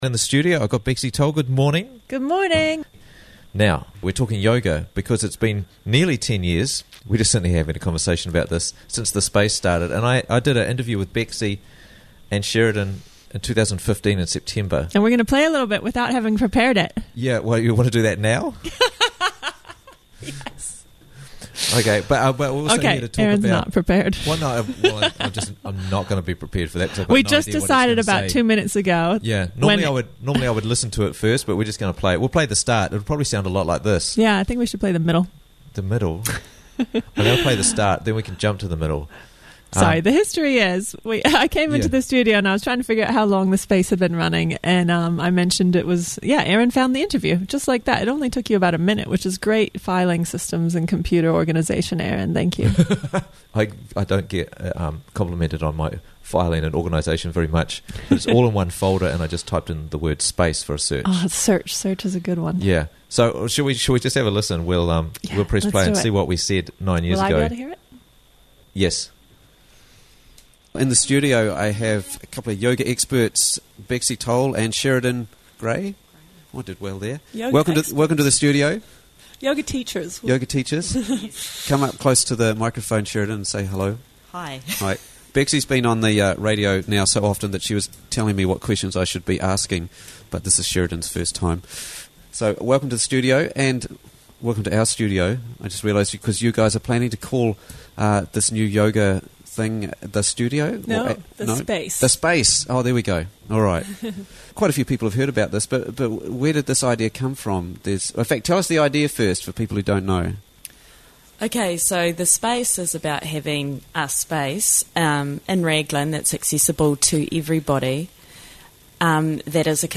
The Space in Raglan for 9 Years - Interviews from the Raglan Morning Show